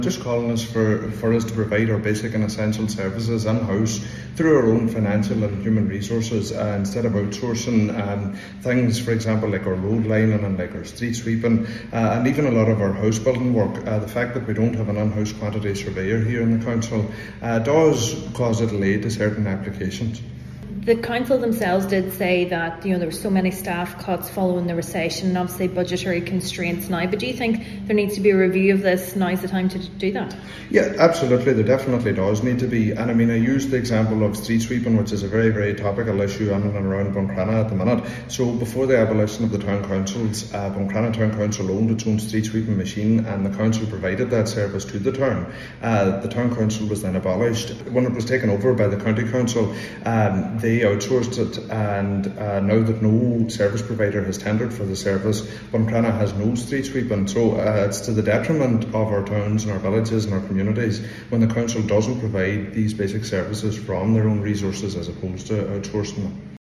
Councillor Bradley believes a review of the current process needs to be carried out: